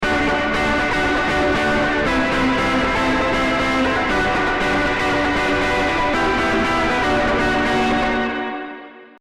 Обработанный ревербом сигнал врезается со всеми своими отражениями в перегруз, который добавляет гармоник в получаемую палитру. Каждый аккорд начинает сверкать и раздаваться по комнате с невообразимой мощью.
В данном случае гитара обработана двумя эффектами на одних и тех же настройках: Wampler Pinnacle Distortion и Strymon Flint (80’s hall Reverb). Только в первом случае использована классическая связка с дисторшном ДО ревера, а во-втором - с дисторшном ПОСЛЕ ревера.
Rever-before-Distortion.mp3